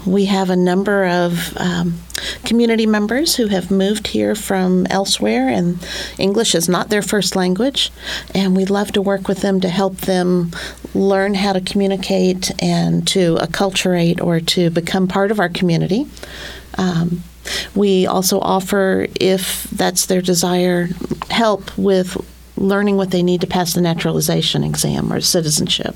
a recent guest of the Talk of the Town on KTLO-FM